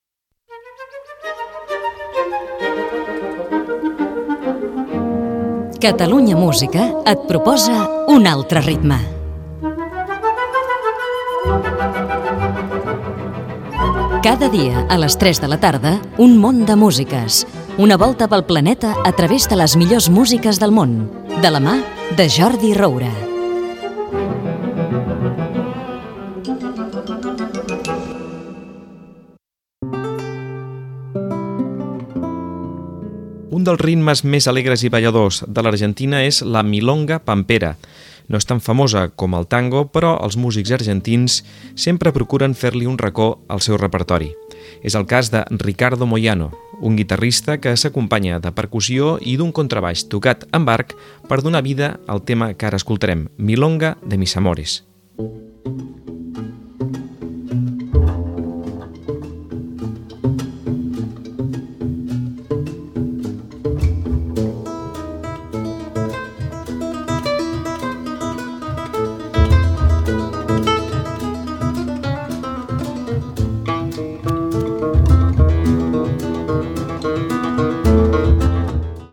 Primer programa. Careta del programa, comentari sobre la milonga tanguera, tema musical
Musical